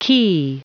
Prononciation du mot quay en anglais (fichier audio)
Prononciation du mot : quay